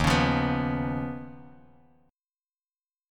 Eb7sus2#5 chord